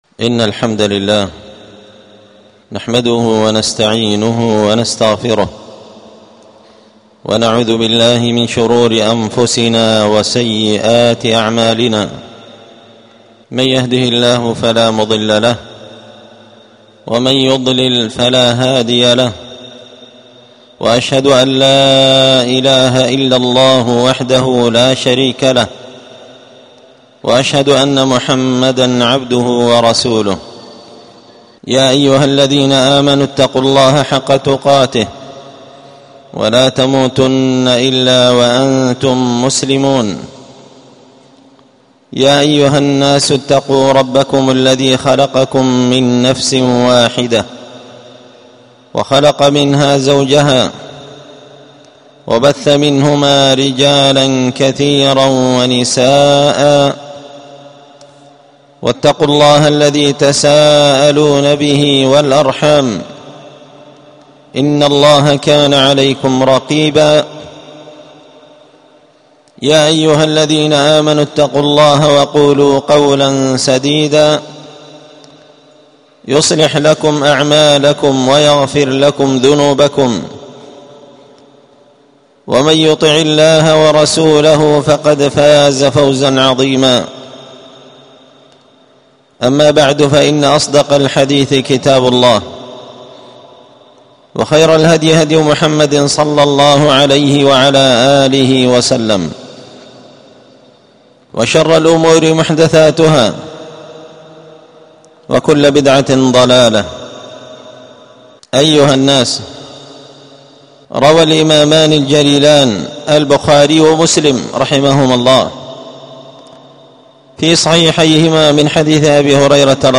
خطبة جمعة بعنوان
ألقيت هذه الخطبة بدار الحديث السلفية